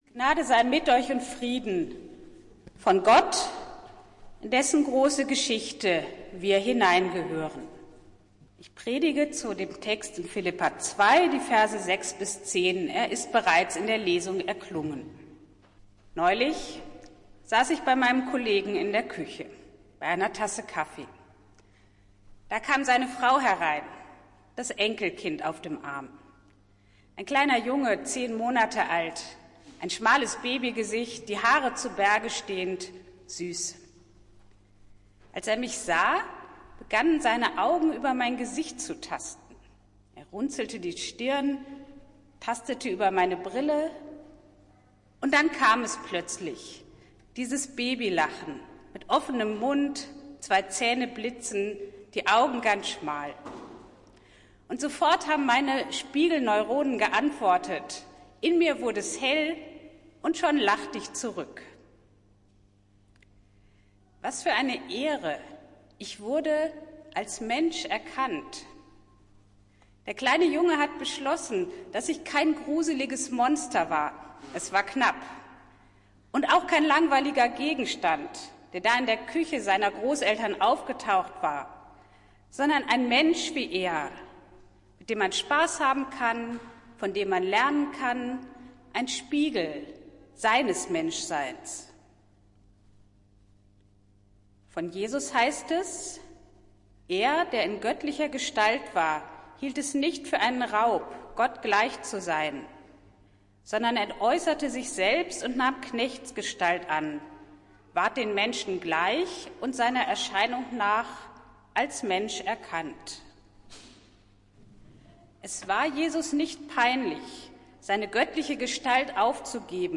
Predigt des Gottesdienstes aus der Zionskirche am Sonntag, den 24. März 2024